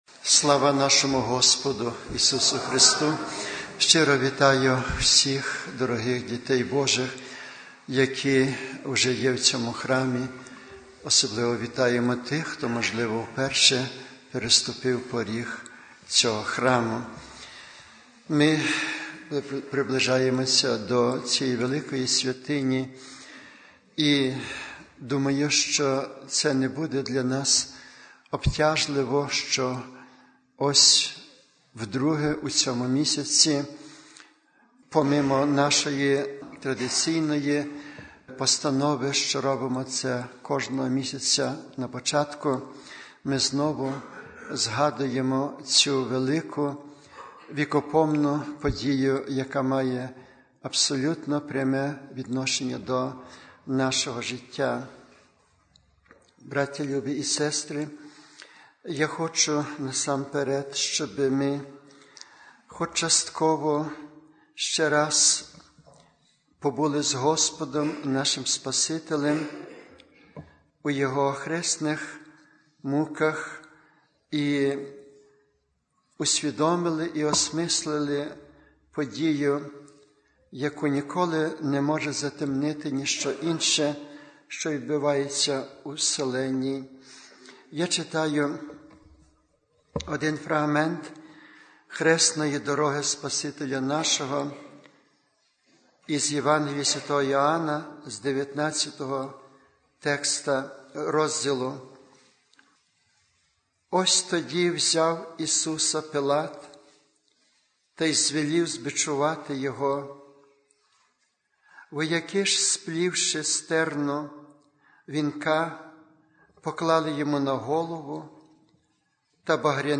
Аудіозаписи проповідей